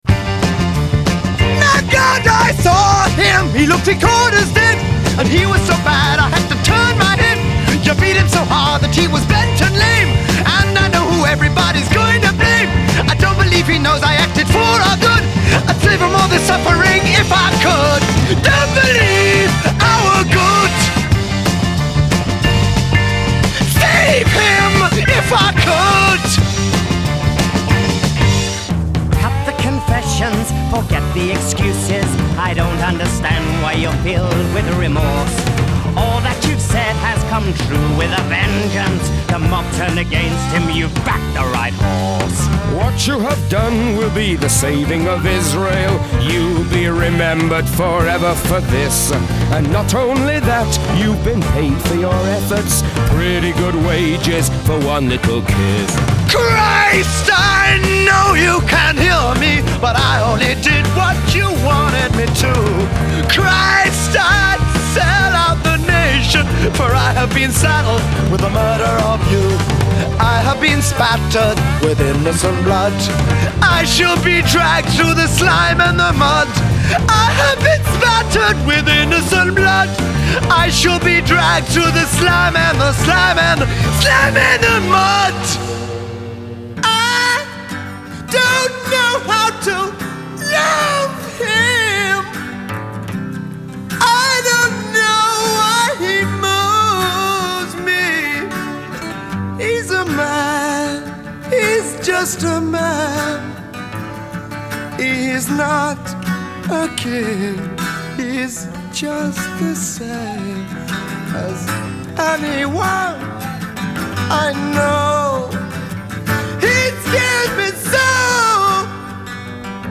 Genre : Musical Theatre